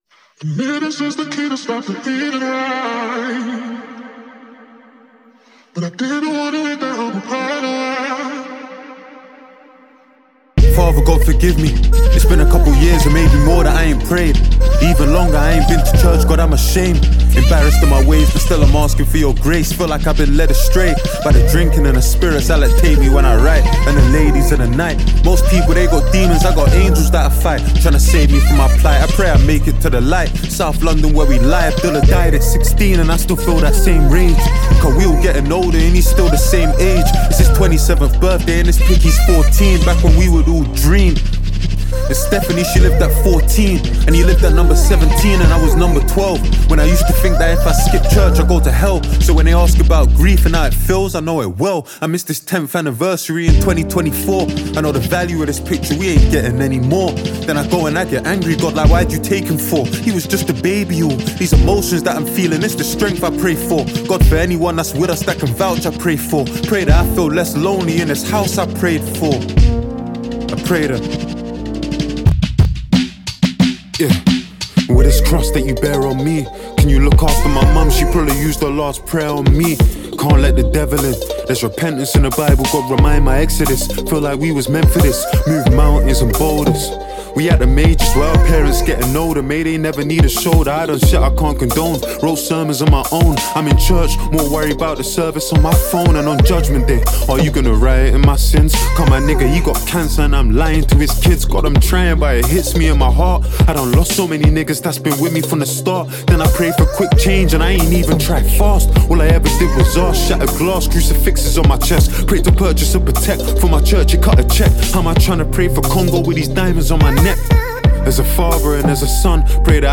British rapper